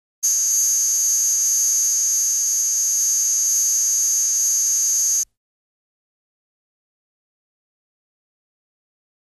Electronic Buzzer; Long, Single Buzz.